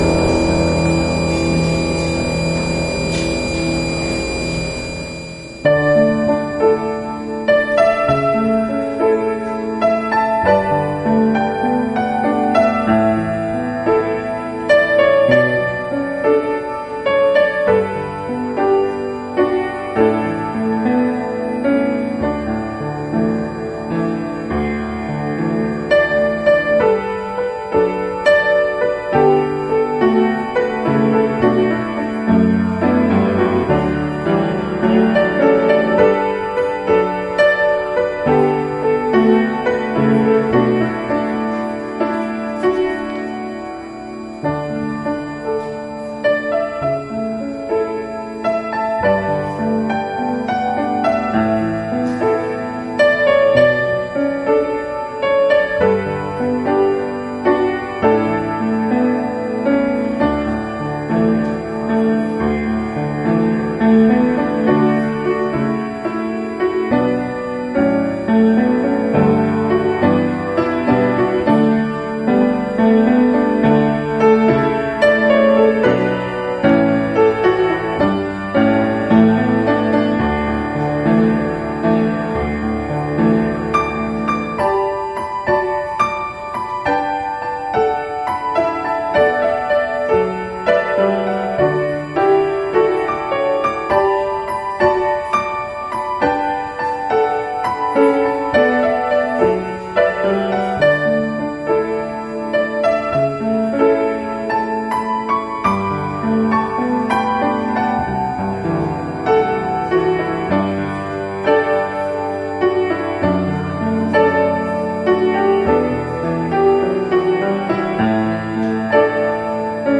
Sermons Archive - Page 2 of 12 - Christ Lutheran Church